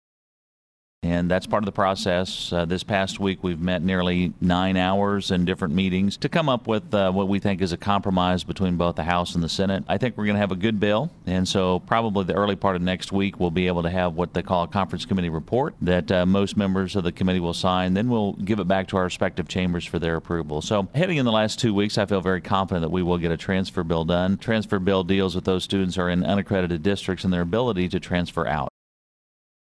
The following audio comes from the above interview with Sen. Pearce, for the week of April 27, 2015.